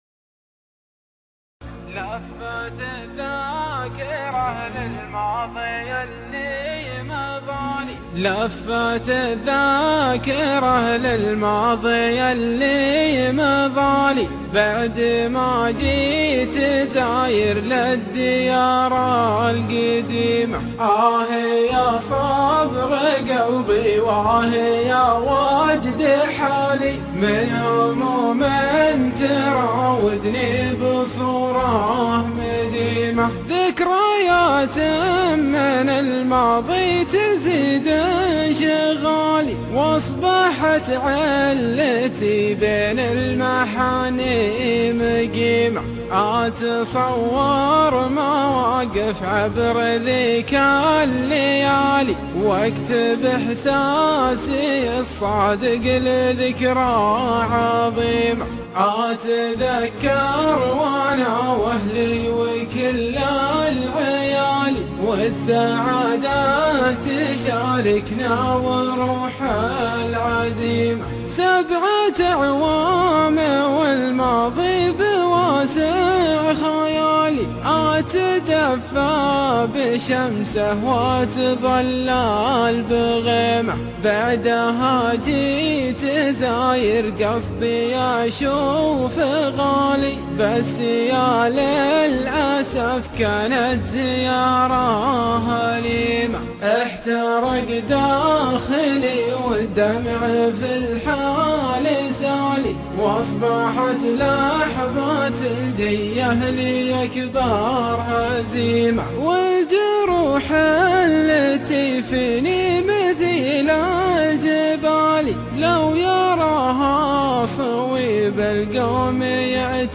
شيلة